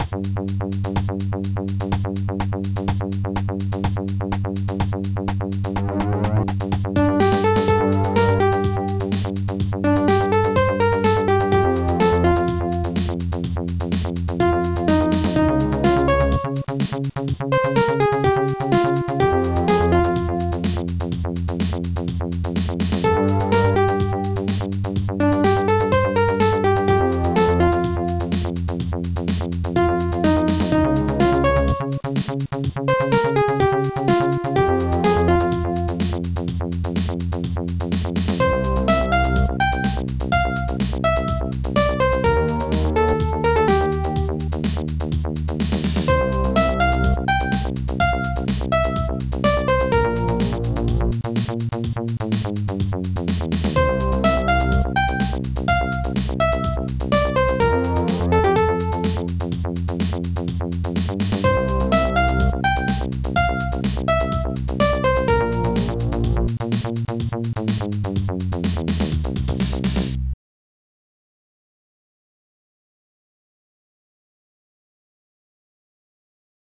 Instruments ringpiano popsnare2 hihat2 bassdrum2 digdug analogstring woodblock
PianoSong.mp3